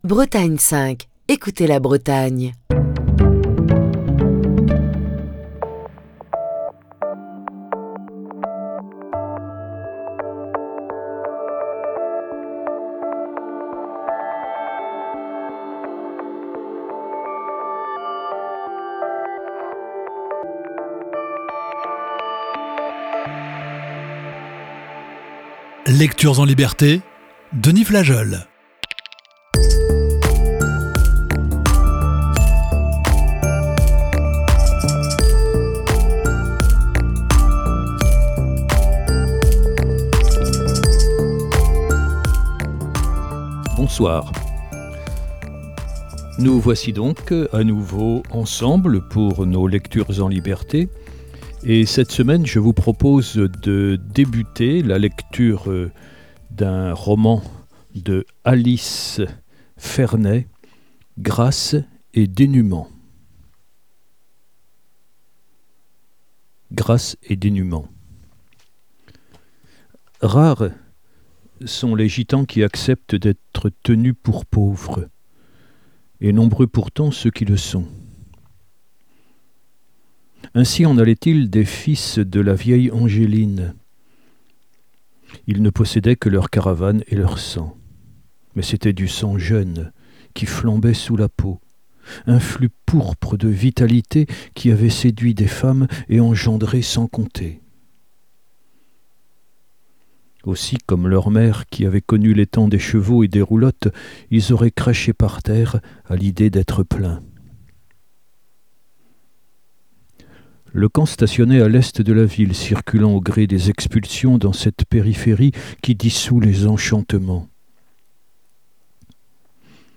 Voici ce soir la première partie de ce récit.